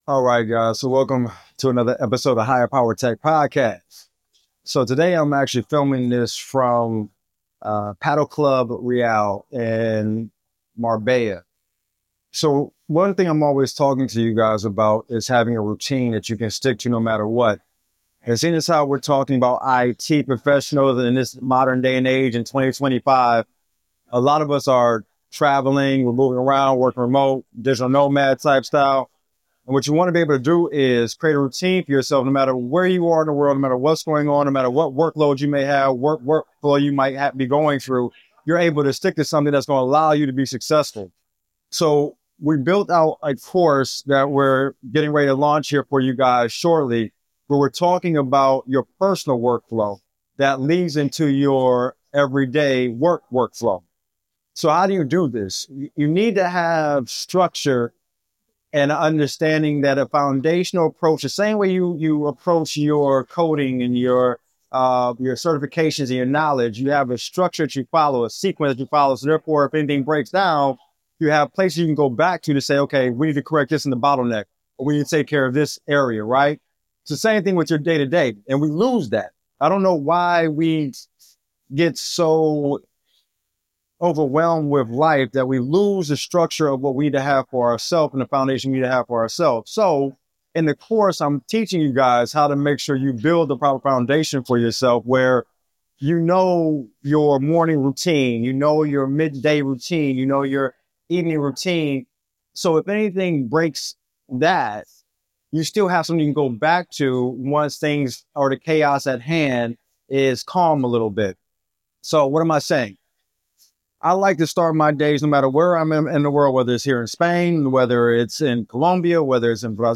Broadcasting from Marbella, Spain, we dive into the importance of building a personal routine that holds steady no matter where you are in the world.